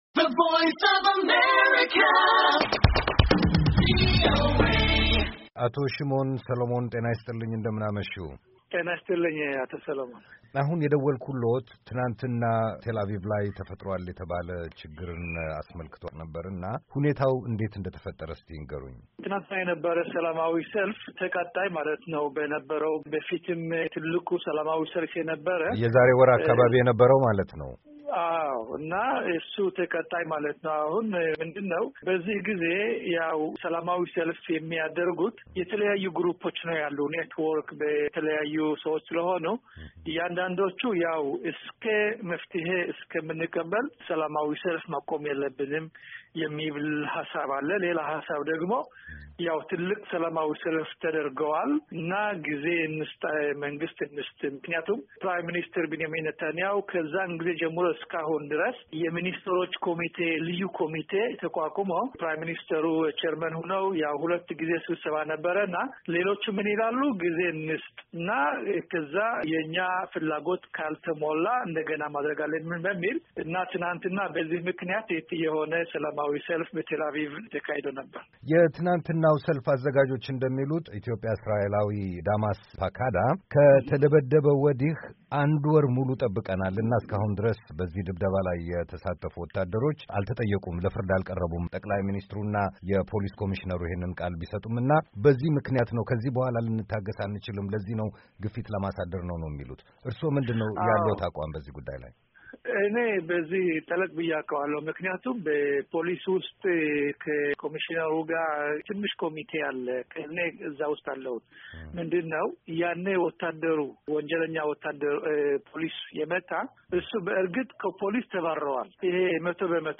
ከቀድሞ የእሥራኤል ፓርላማ አባል ሺሞን ሰሎሞን ጋር የተደረገ ቃለ-ምልልስ
Interview with Shimon Solomon, Former Israeli Knesset Member, 06-05-15